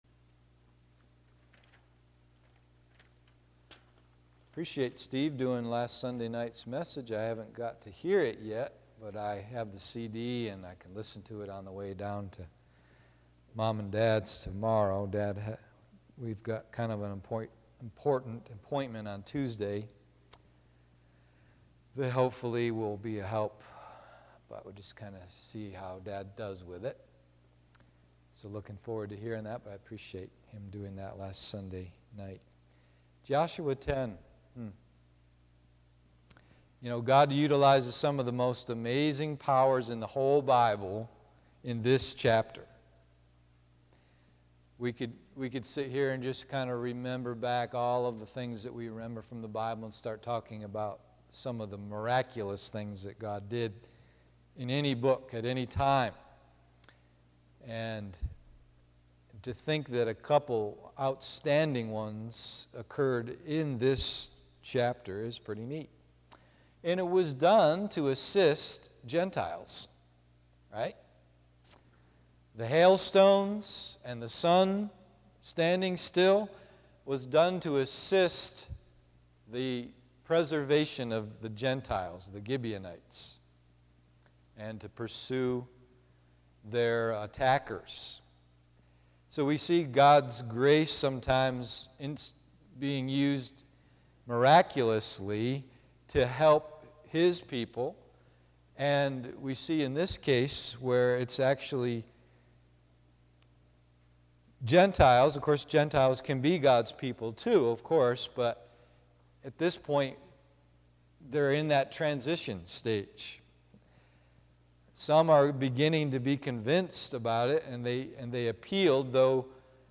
Joshua 10:11-14 PM Service